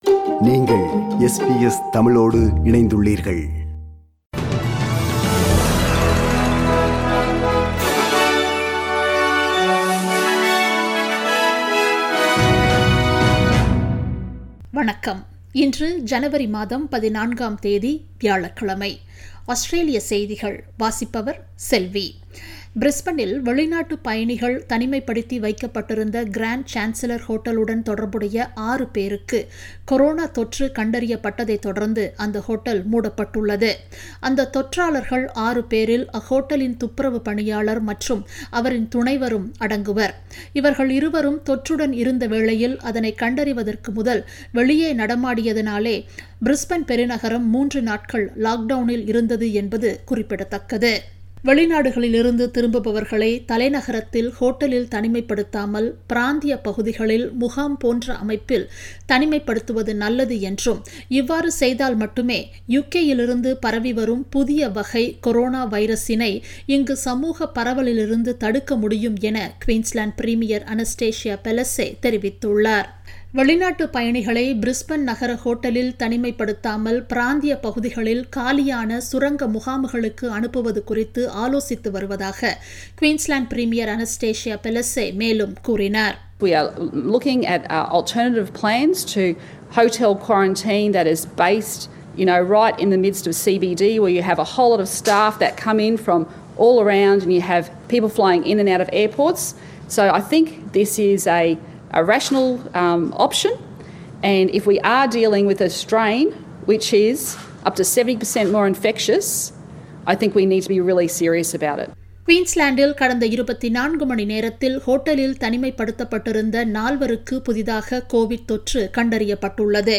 Australian news bulletin for Thursday 14 January 2021.